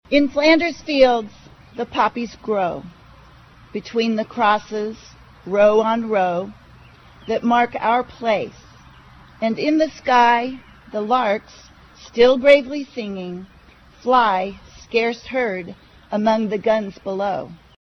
Under a partly cloudy sky, Emporians gathered at the All Veterans Memorial to pay their respects to military service personnel who never made it home from combat as well as those who passed away over the last year.
The city’s annual Memorial Day service featured readings